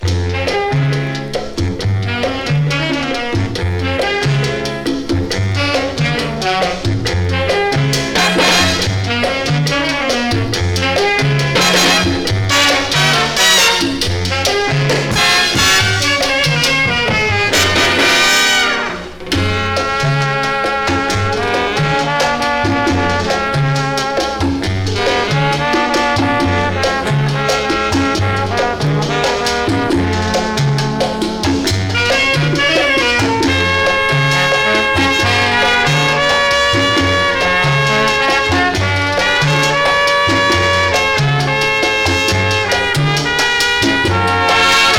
Latin, World　USA　12inchレコード　33rpm　Mono